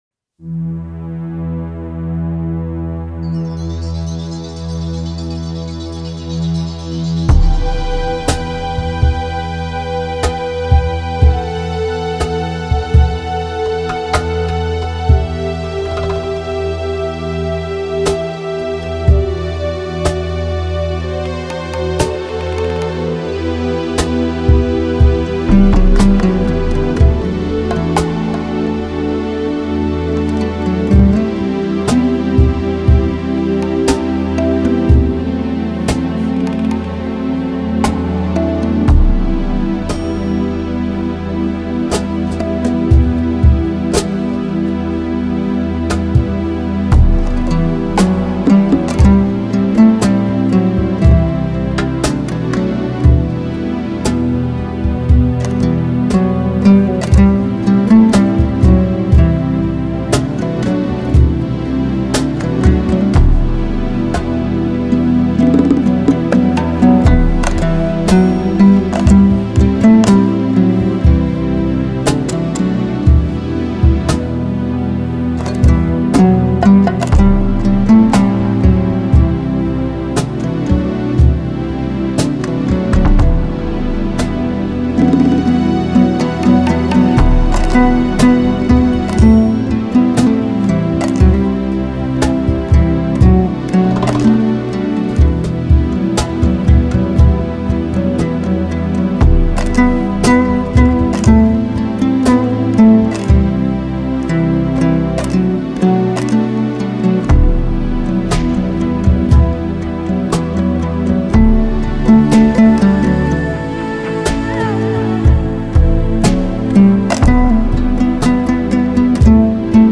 Особенности исполнения заключаются в выразительном вокале